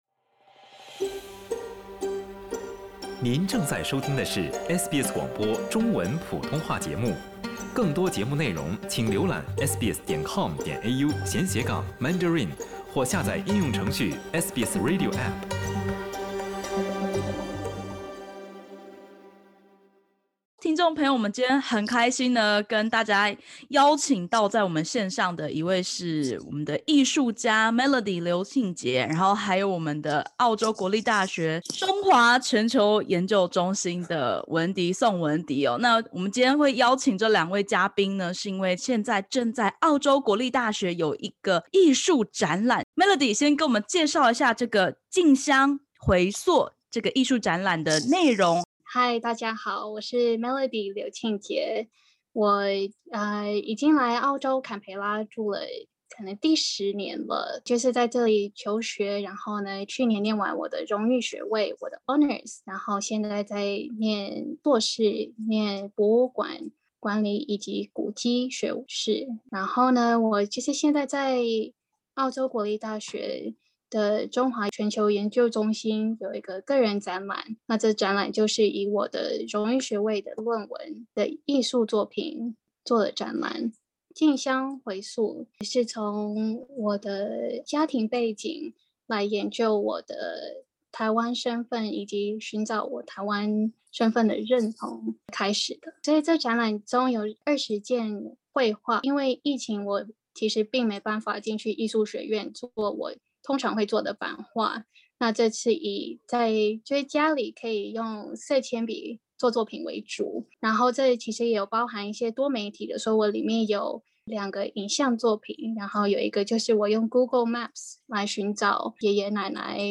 点击首图收听完整采访音频。